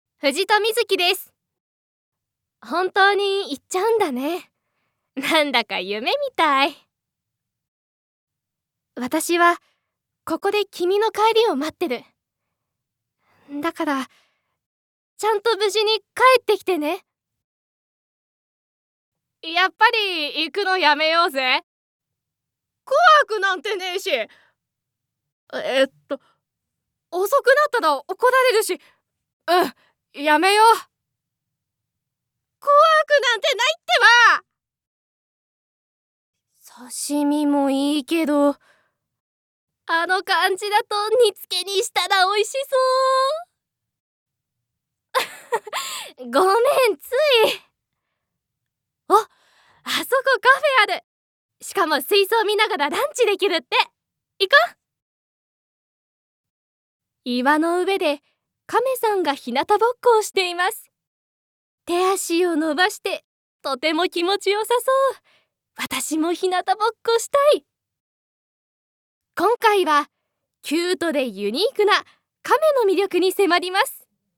One shot Voice（サンプルボイスの視聴）